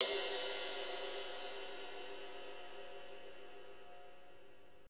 Index of /90_sSampleCDs/Northstar - Drumscapes Roland/DRM_Slow Shuffle/KIT_S_S Kit 1 x
CYM S S RI0R.wav